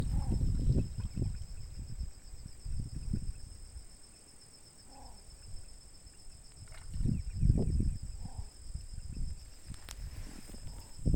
Mirasol Chico (Botaurus exilis)
Nombre en inglés: Least Bittern
Localización detallada: Navegación Rio Miriñay
Condición: Silvestre
Certeza: Fotografiada, Vocalización Grabada